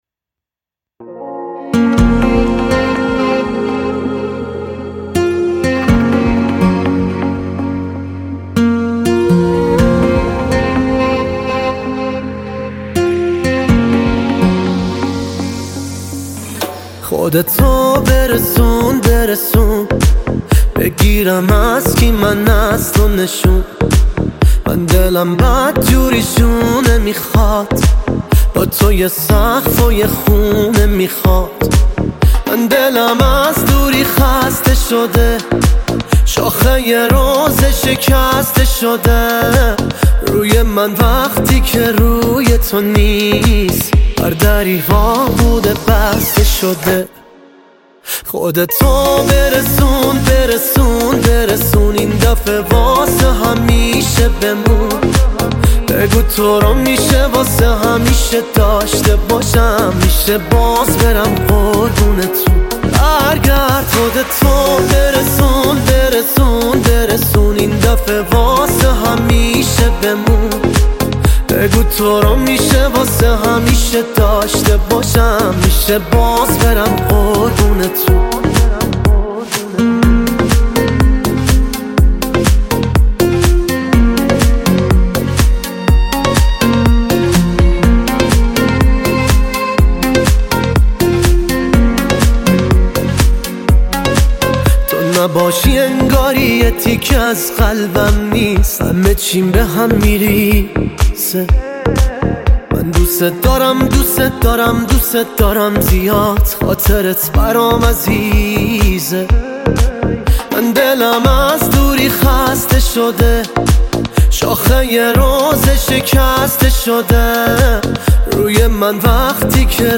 • دسته بندی ایرانی پاپ